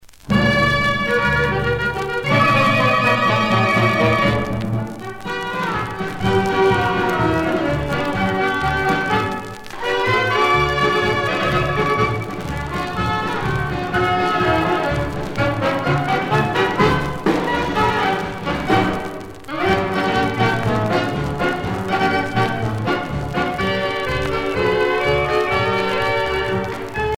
danse : paso-doble